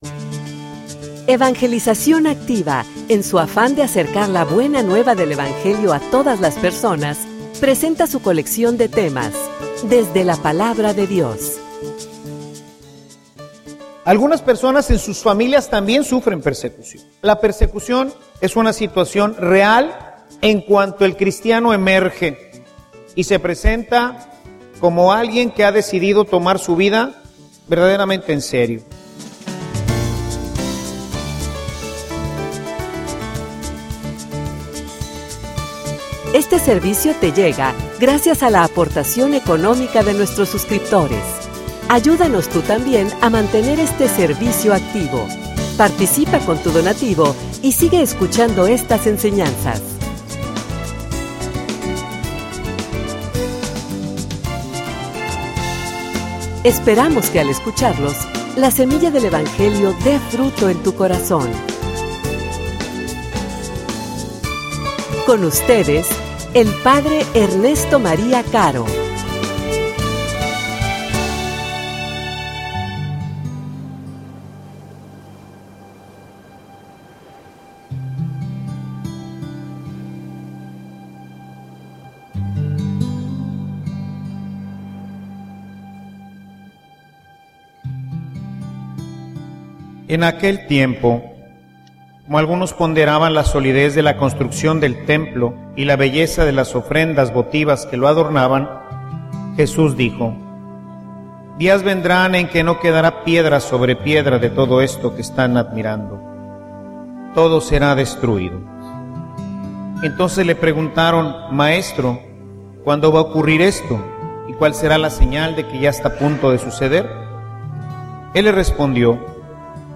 homilia_Preparados_para_la_persecucion.mp3